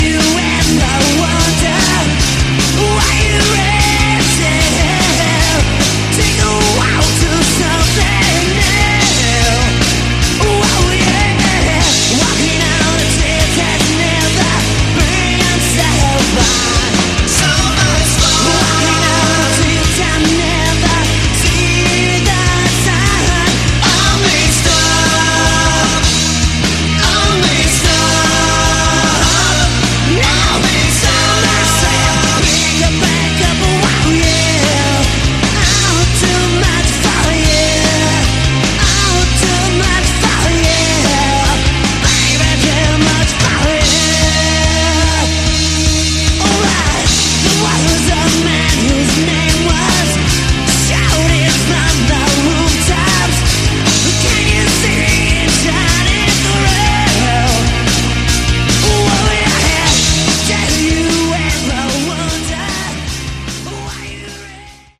Category: Sleaze Glam
lead vocals
guitars
bass
drums